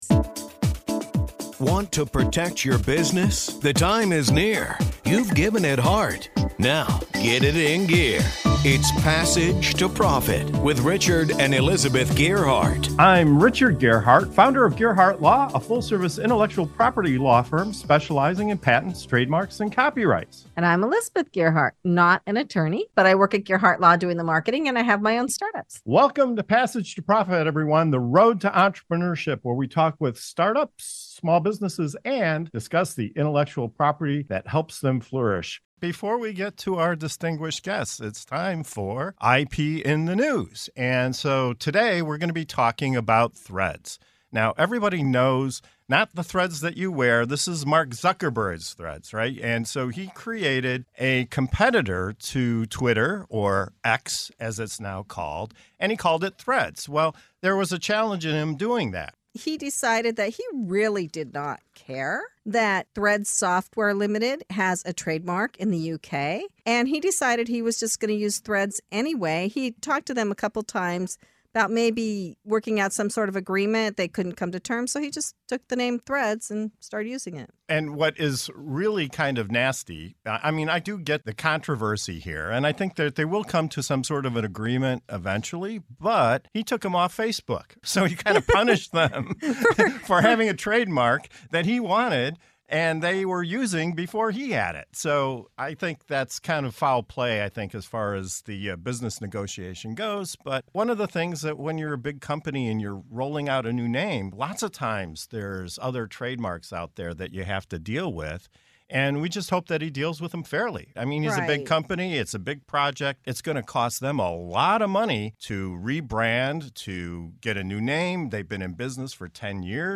Join us in this IP in the News segment of the Passage to Profit Show - Road to Entreprenuership as we unravel the legal entanglement in the world of social media titans. In this episode, we dive into Mark Zuckerberg's audacious move with 'Threads,' his Twitter X challenger, and the heated trademark dispute that ensued with Threads Software Limited.